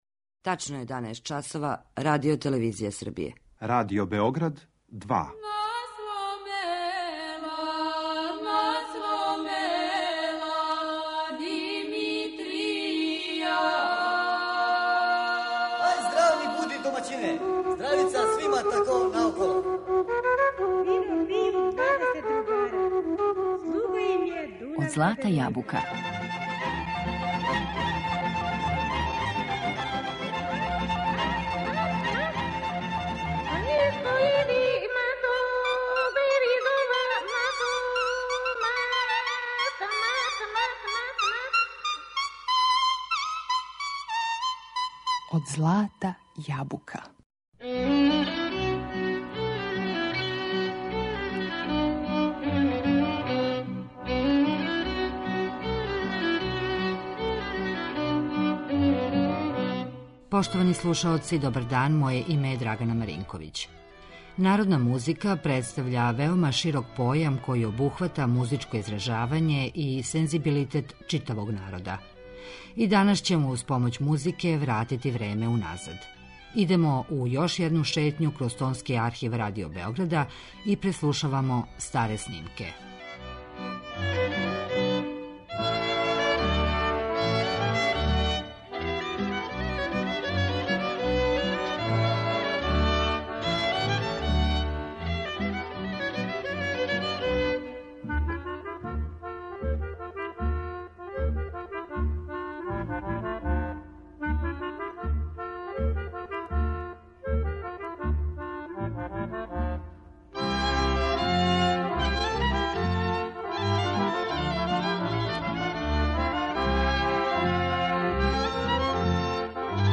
Снимци већ скоро заборављених уметника